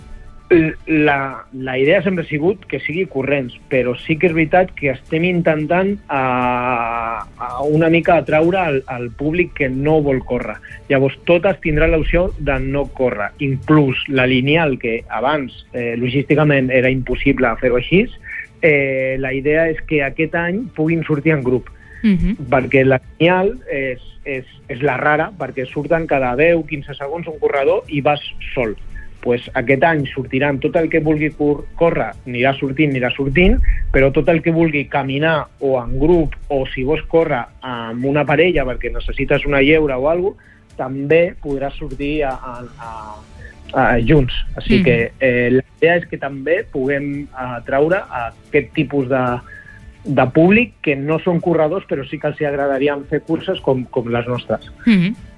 Supermatí - entrevistes
I per parlar-ne ens ha visitat al Supermatí el regidor d’esports de l’Ajuntament de Castell d’Aro, Platja d’Aro i s’Agaró, Marc Medina.